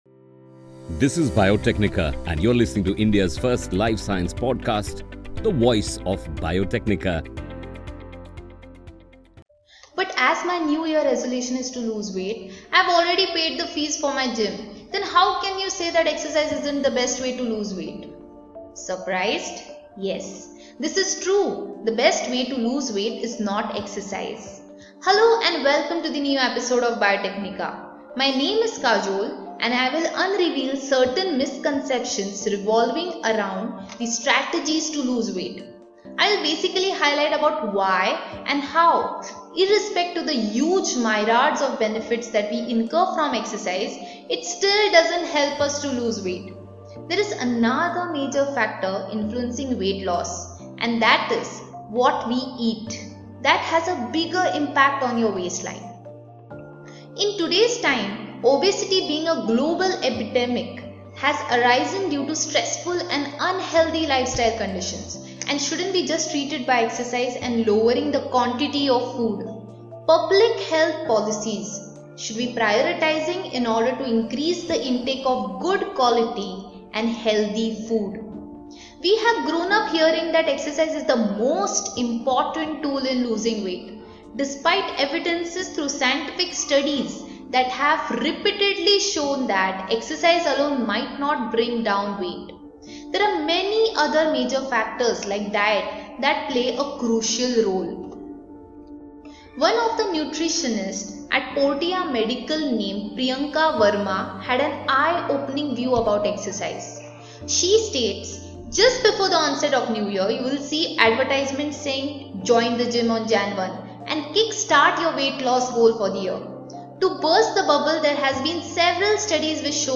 In this episode , our speaker will unravel certain misconceptions revolving around the strategies to lose weight.